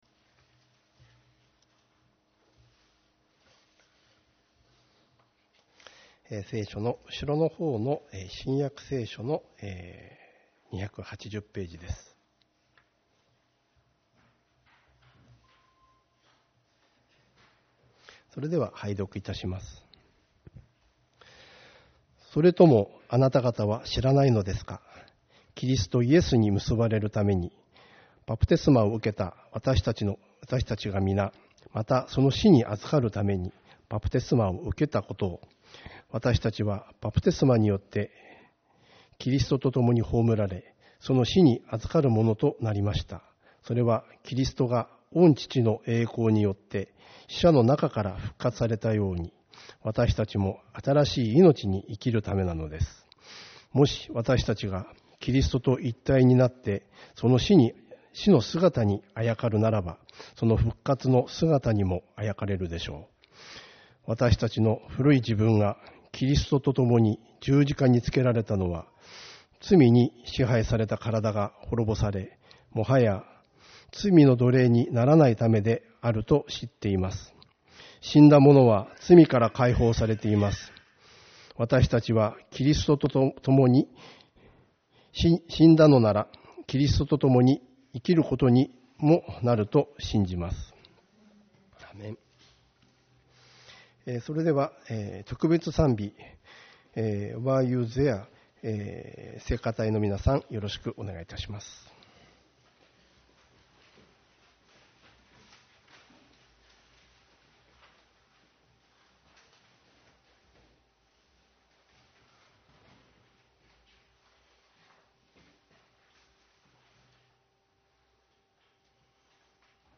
イースター礼拝 「復活の命に生きる｣ ローマの信徒への手紙6:3-8